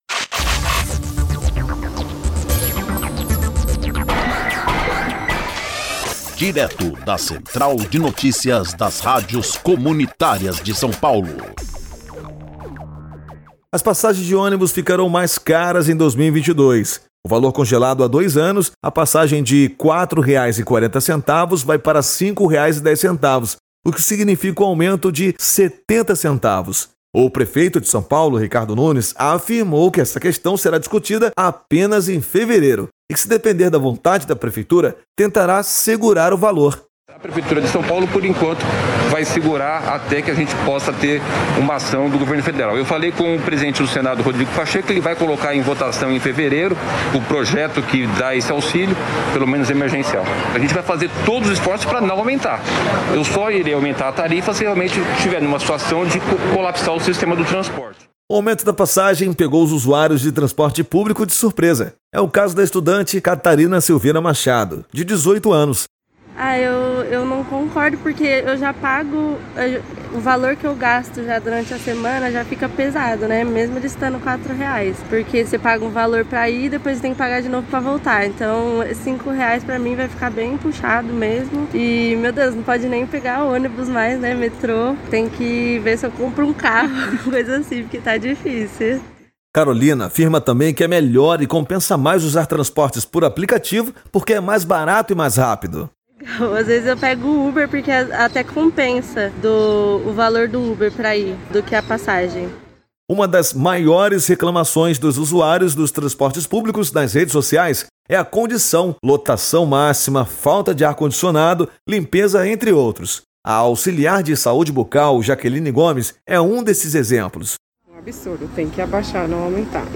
1-NOTICIA-AUMENTO-PASSAGEM-DE-ONIBUS-LIBERTACAO.mp3